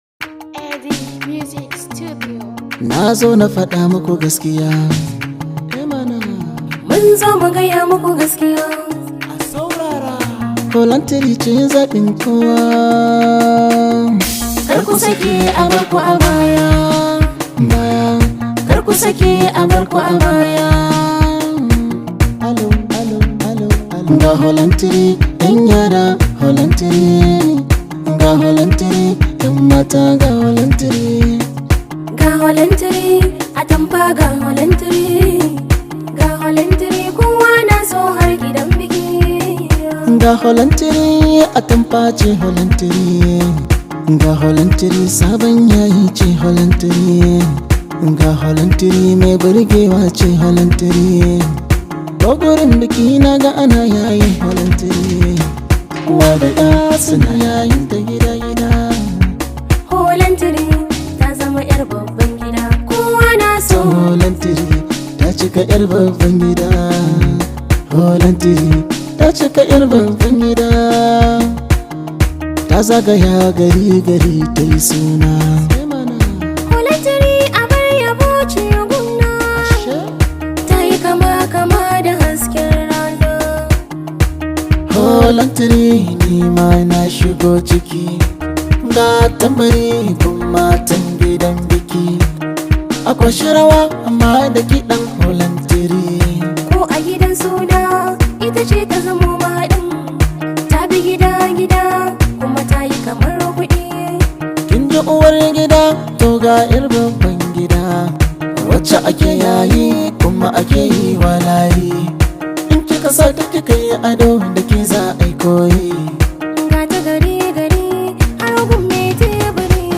Hausa Songs
high vibe hausa song